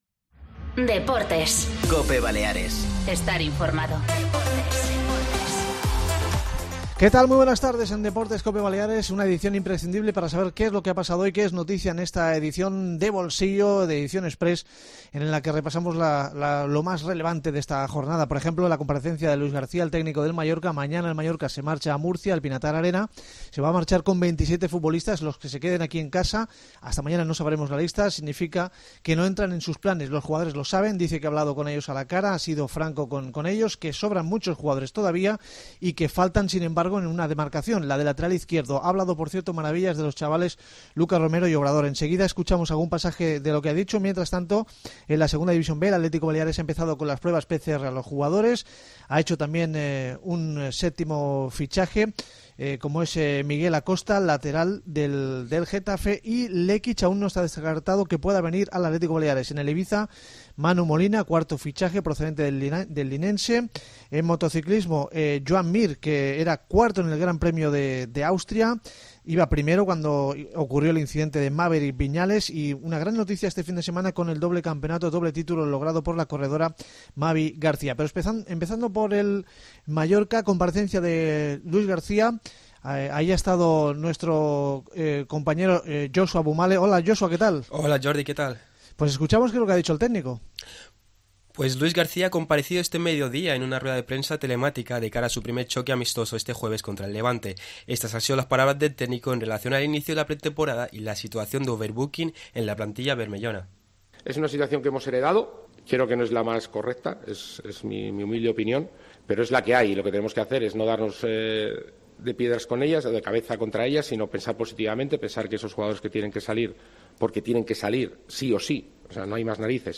Escuchamos a Luis Gracía Plaza, entrenador del Mallorca, y repasamos algunos de los titulares del día